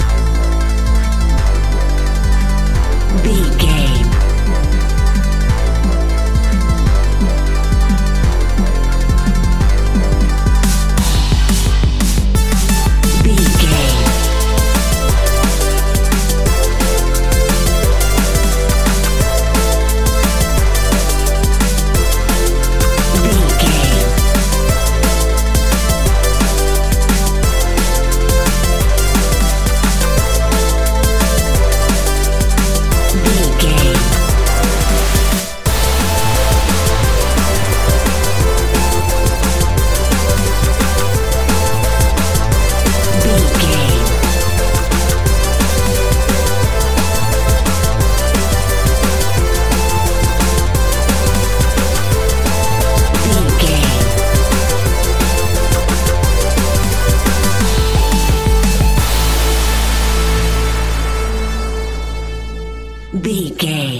Epic / Action
Fast paced
Aeolian/Minor
aggressive
dark
driving
energetic
frantic
futuristic
synthesiser
drum machine
electronic
sub bass
synth leads
synth bass